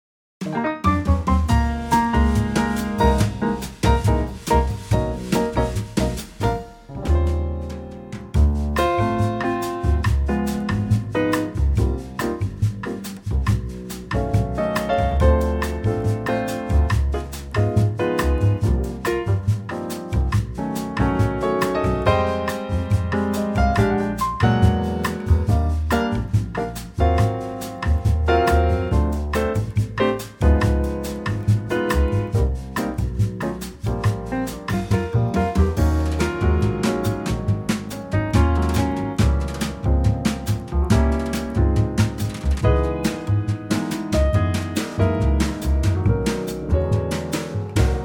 key Bb
key - Bb - vocal range - Ab to Eb
Wonderful Trio arrangement
bossa nova classic, at a great tempo.